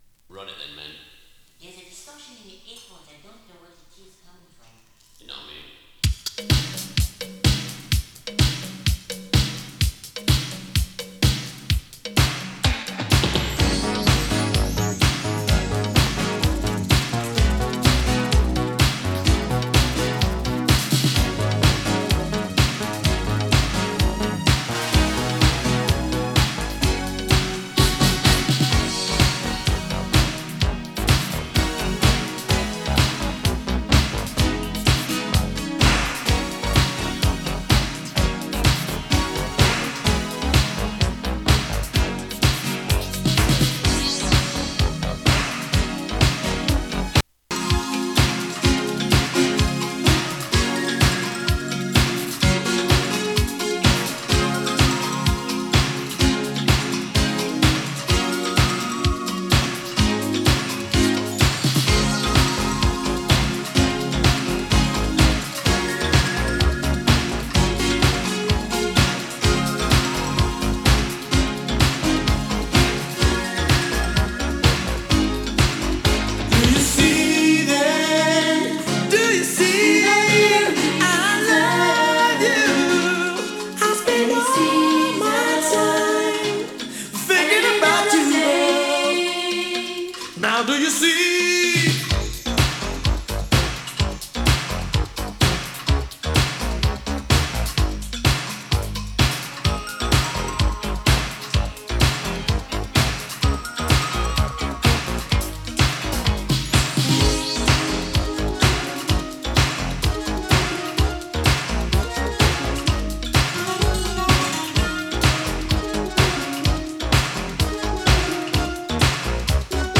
モダンソウル